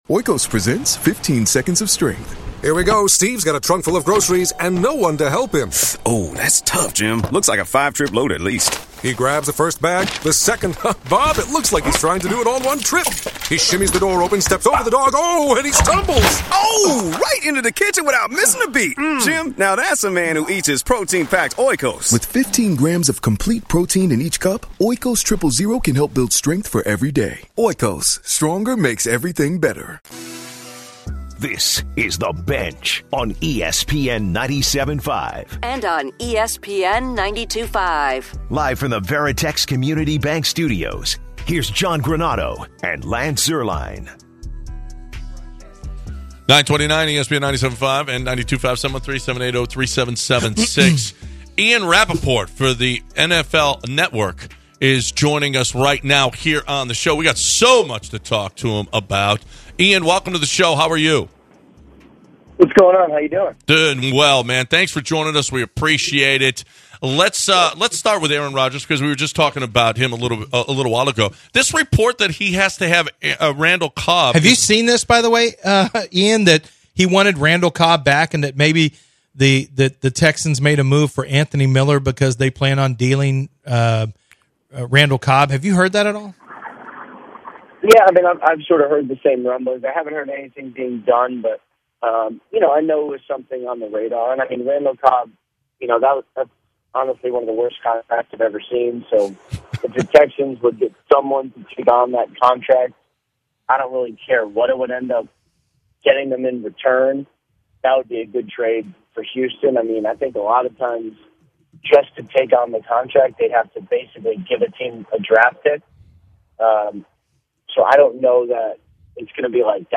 NFL Insider Ian Rapoport joins The Bench